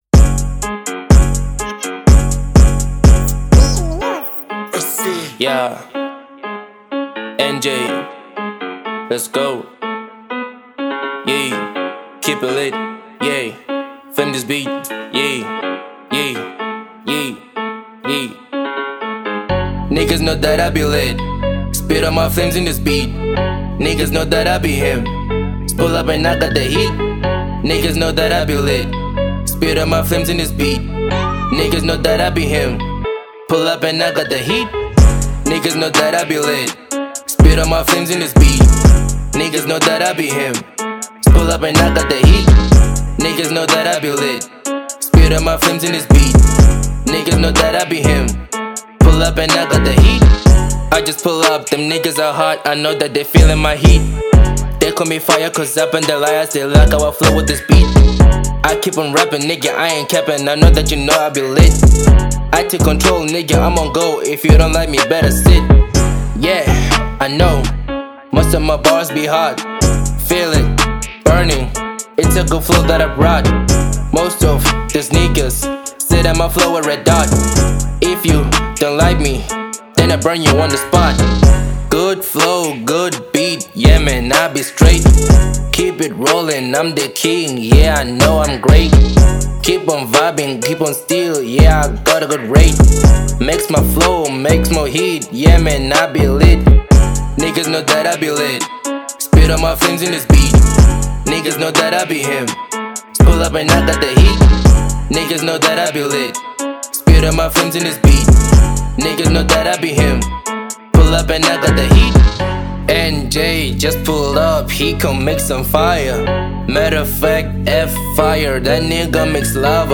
03:05 Genre : Hip Hop Size